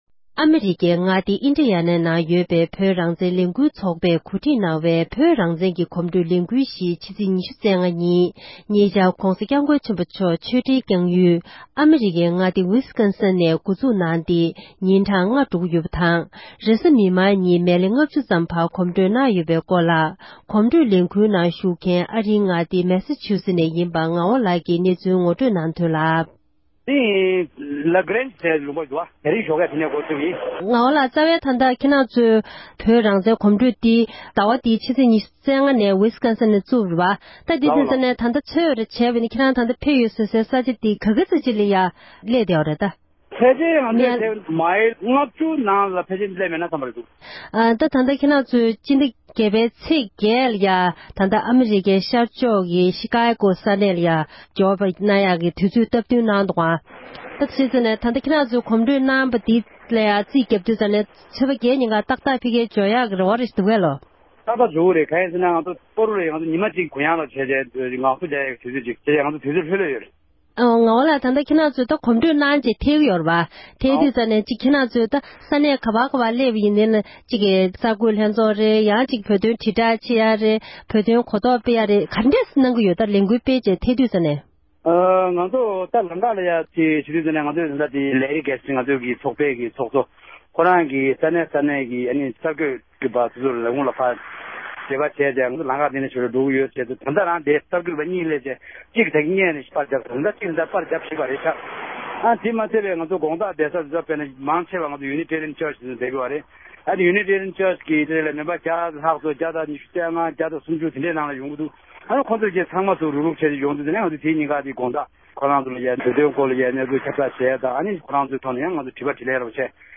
འབྲེལ་ཡོད་མི་སྣར་བཀའ་འདྲི་ཞུས་ཏེ་གནས་ཚུལ་ཕྱོགས་སྒྲིག་ཞུས་པར་ཞིག་གསན་རོགས་གནང༌༎